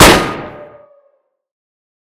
snd_revolver_shoot.wav